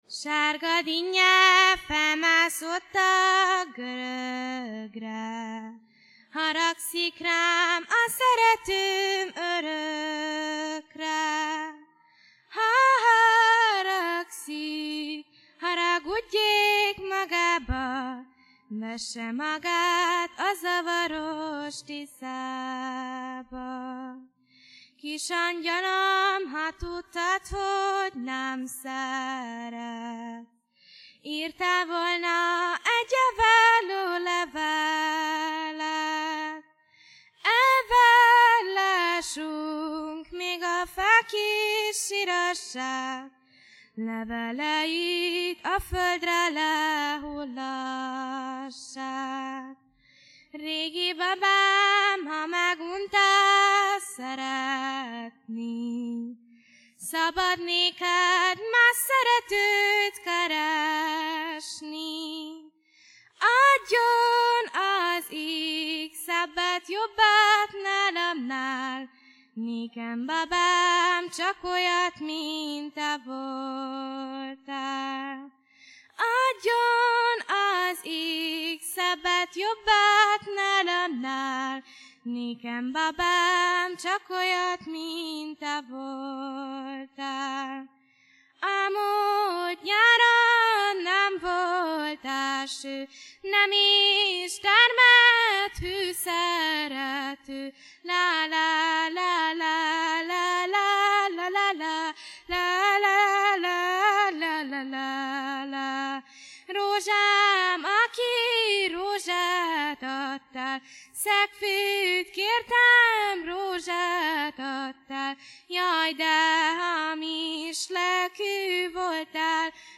A Jégcsarnokban rögzített hanganyag
Mezöségi népdalok